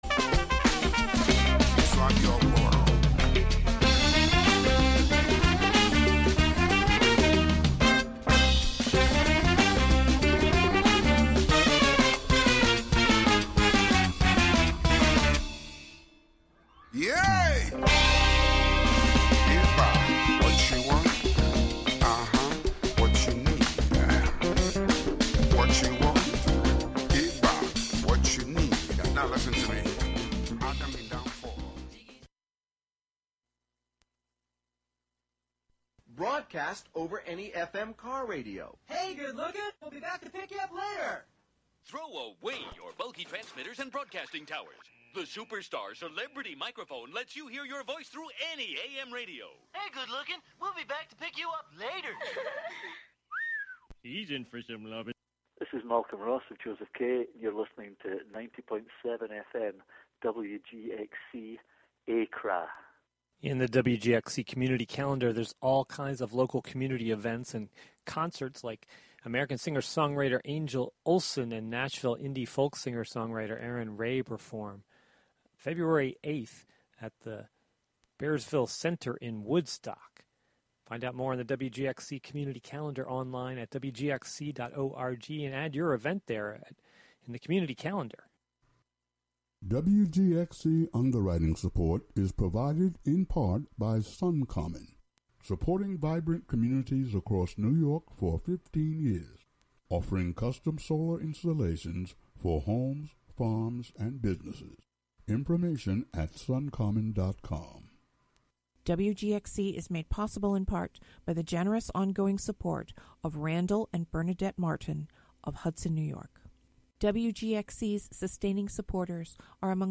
guitarist